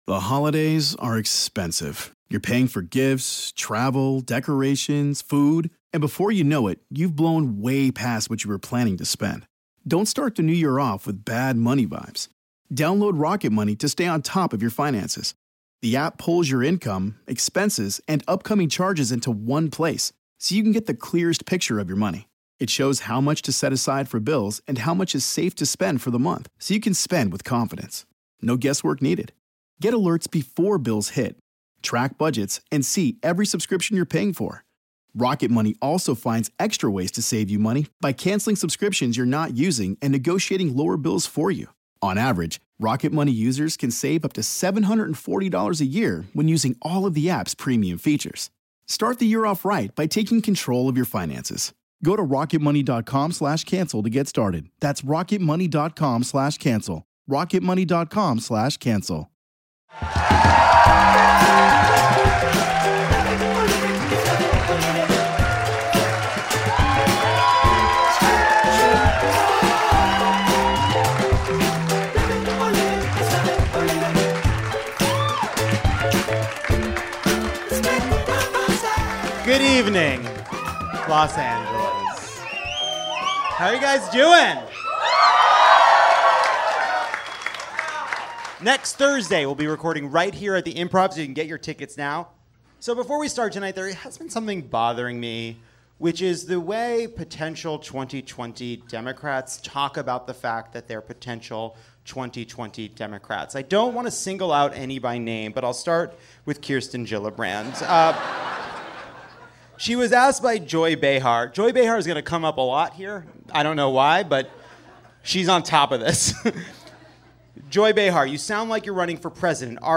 Trump's White House is in turmoil over Omarosa's recordings. So we asked Lauren Mayberry of Chvrches to perform a dramatic reading of Omarosa's dramatic prologue.